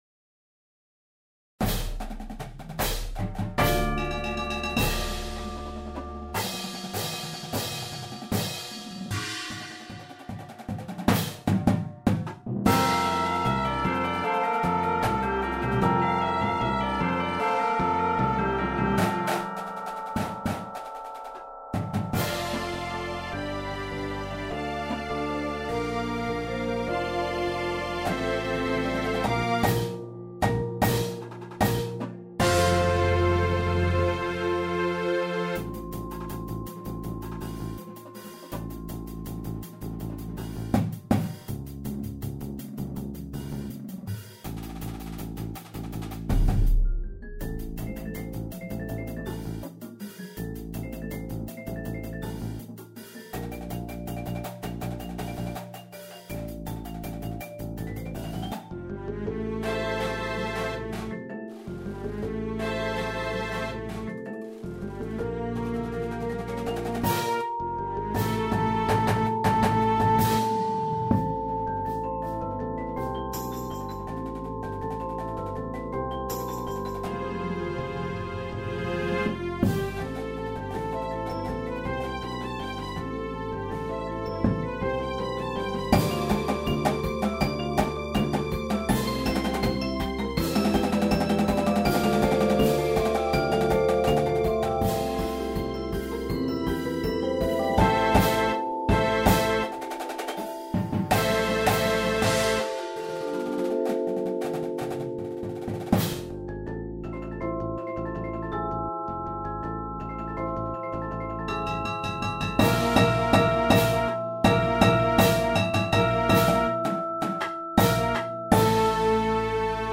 Indoor Percussion piece
Snares
Tenors
Basses (5)
Cymbals
Marimba 1, 2
Xylophone
Vibes 1, 2
Chimes
Timpani
Bass Guitar
Synth